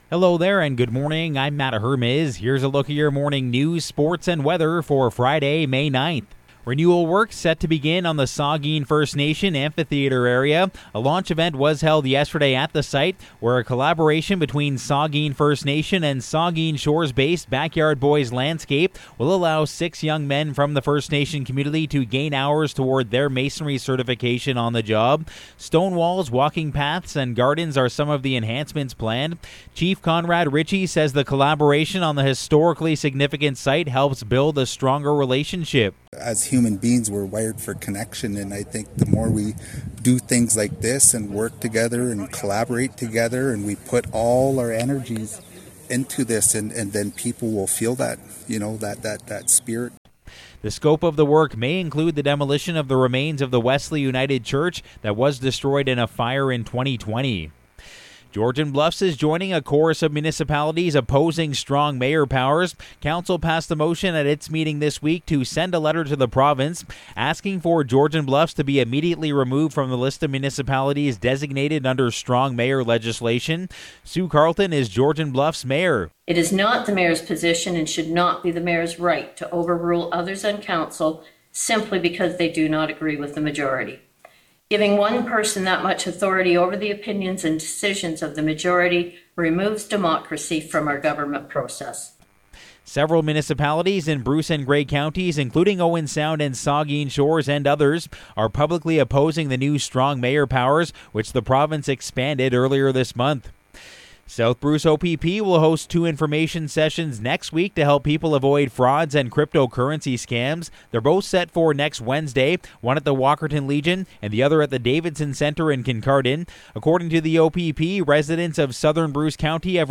Morning News – Friday, May 9